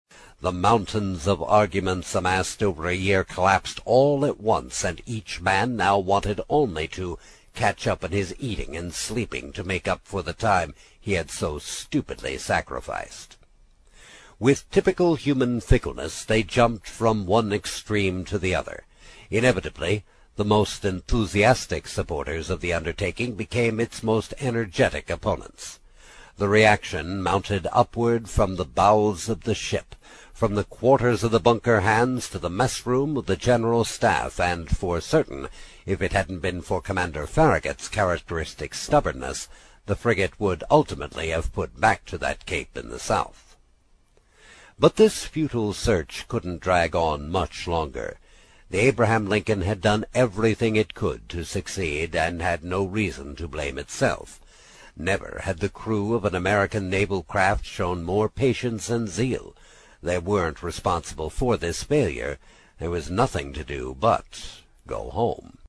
在线英语听力室英语听书《海底两万里》第54期 第5章 冒险活动(9)的听力文件下载,《海底两万里》中英双语有声读物附MP3下载